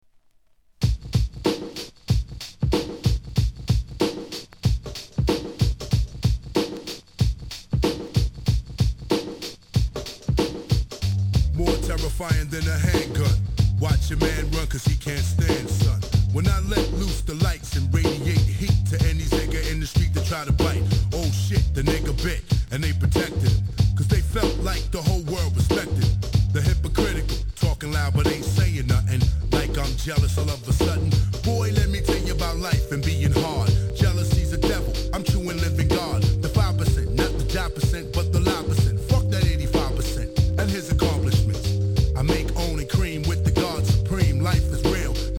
HIP HOP/R&B
フックにソウルフルなコーラスを交えた逸品!!